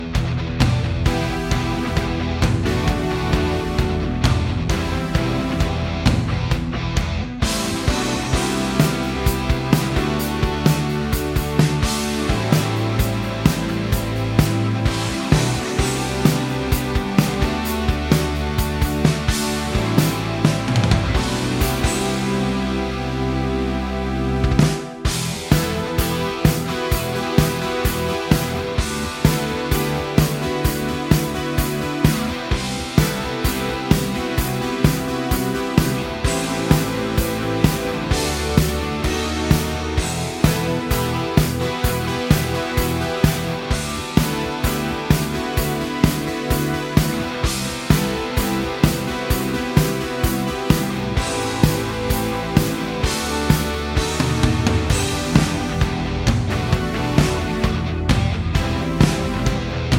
Minus Main Guitars For Guitarists 5:23 Buy £1.50